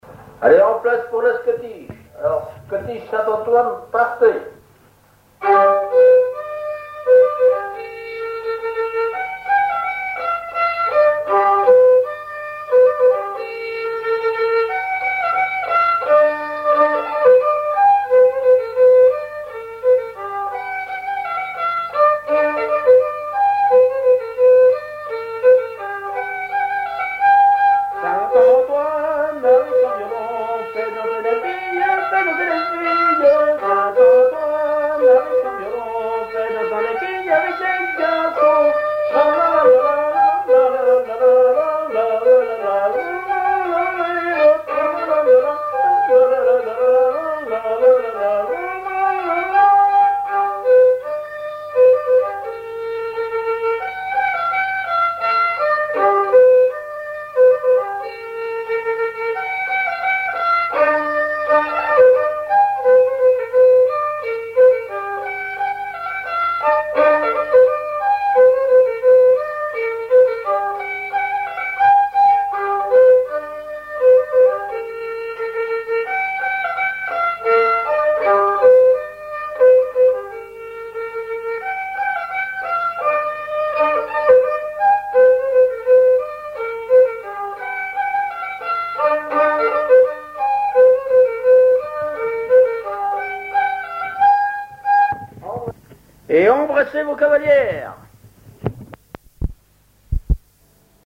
Chants brefs - A danser
danse : scottich trois pas
Pièce musicale inédite